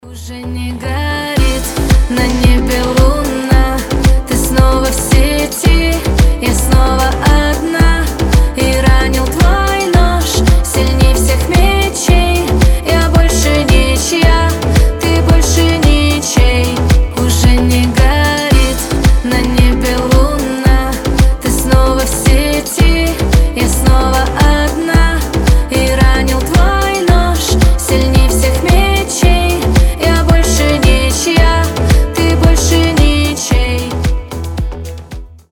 • Качество: 320, Stereo
грустные
женский голос